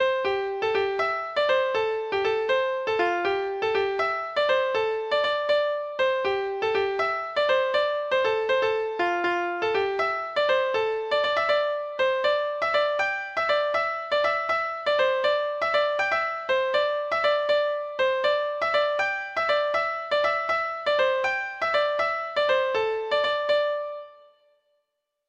Folk Songs from 'Digital Tradition'
Traditional Music of unknown author.